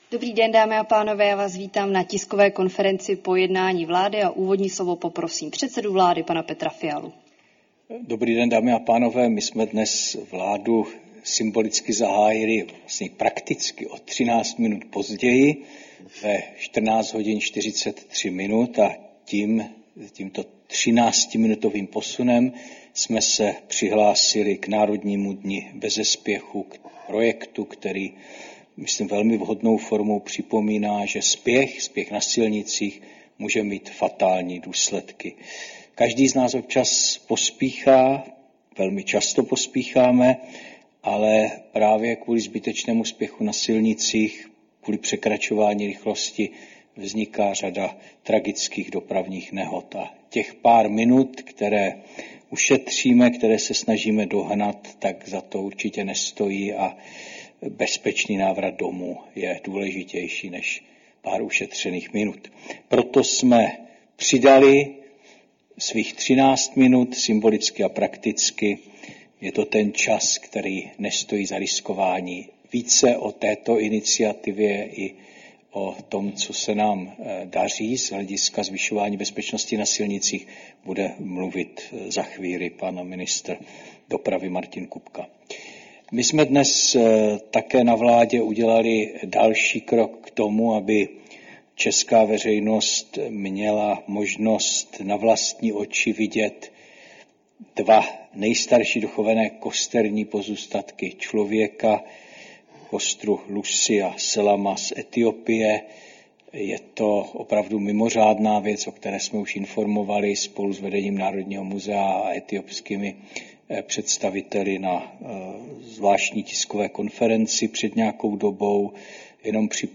Tisková konference po jednání vlády, 23. dubna 2025